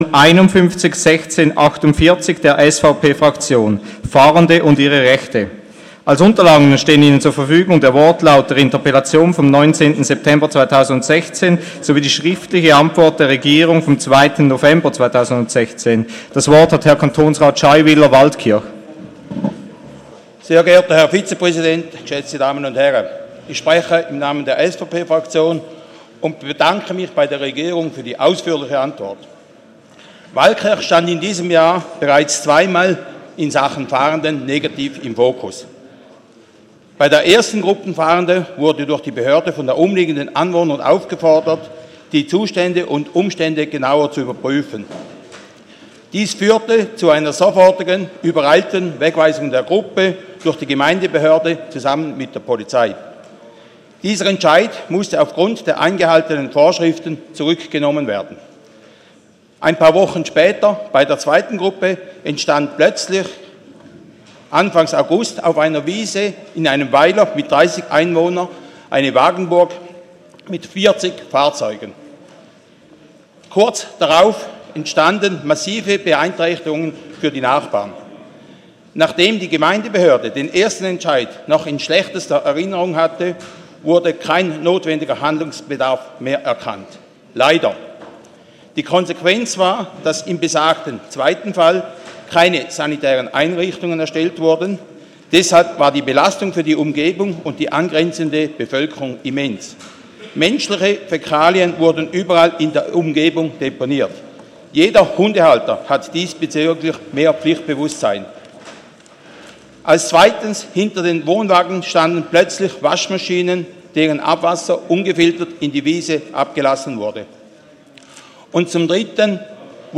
29.11.2016Wortmeldung
Session des Kantonsrates vom 28. und 29. November 2016